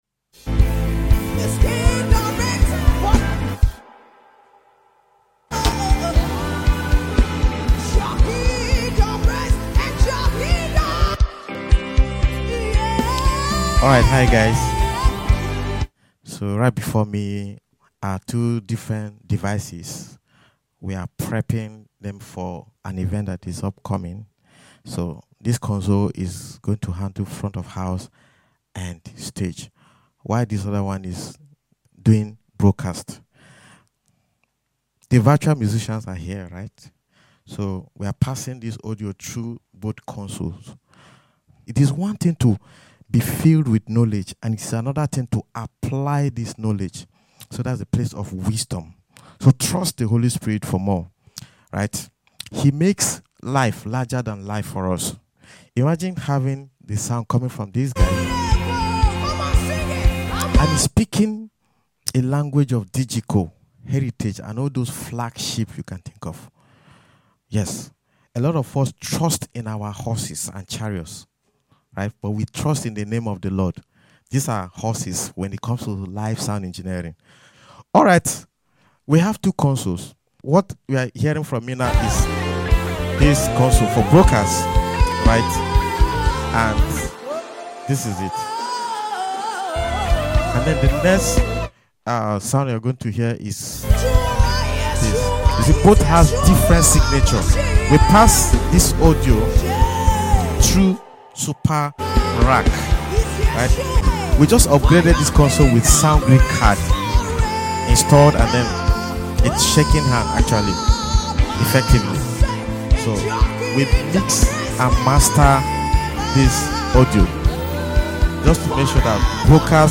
A finalization of audio package before leaving the console to the PA SYSTEM Next Level Of Mixing Precision.